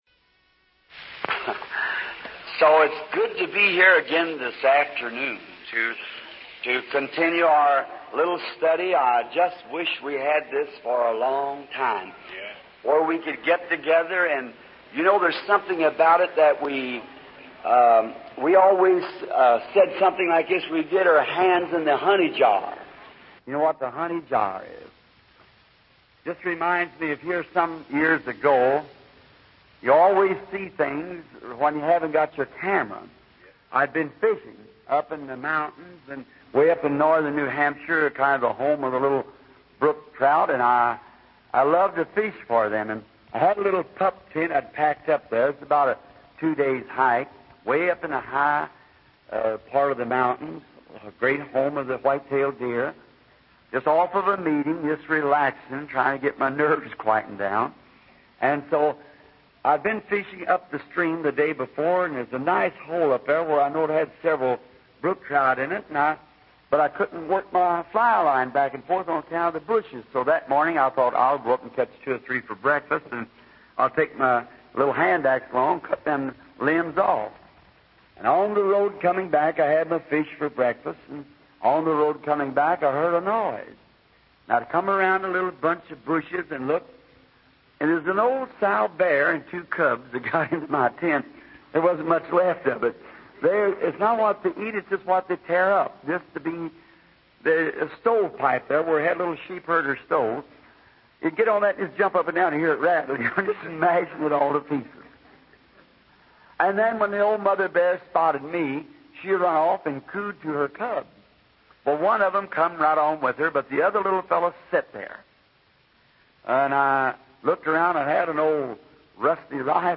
Dieses Portal gibt Ihnen die Möglichkeit, die ca. 1200 aufgezeichneten Predigten